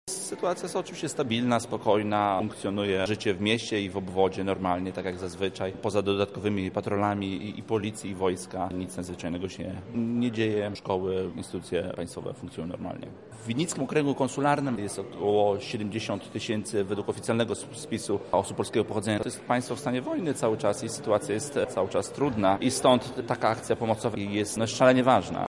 O tym jakie nastroje panują obecnie w tamtym rejonie, mówi konsul generalny Damian Ciarciński:
Konsul generalny